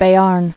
[Bé·arn]